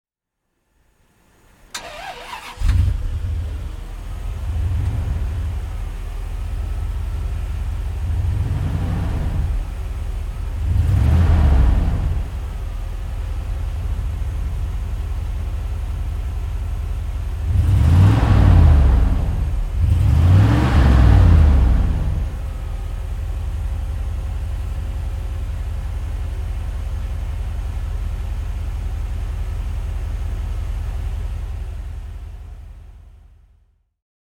Motorsounds und Tonaufnahmen zu Bentley Fahrzeugen (zufällige Auswahl)
Bentley Turbo S (1995) - Starten und Leerlauf